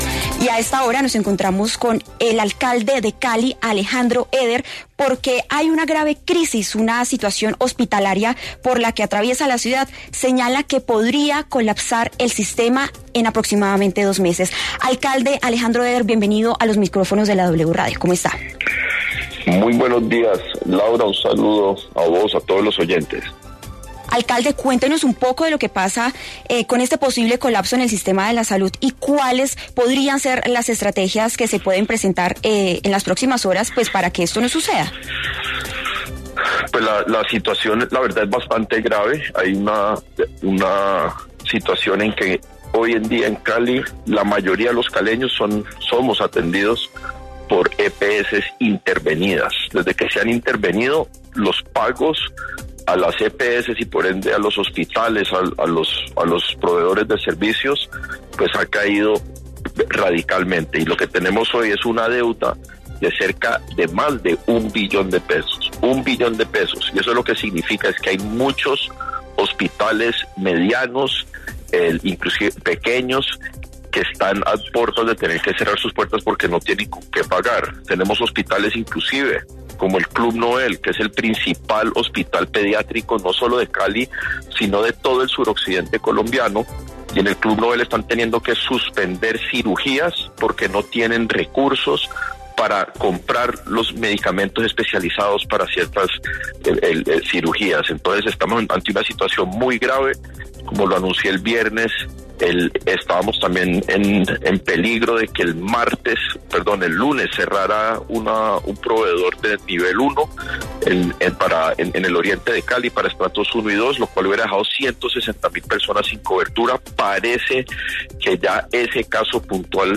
Escuche la entrevista completa a Alejandro Éder, alcalde de Cali, en W Fin de Semana: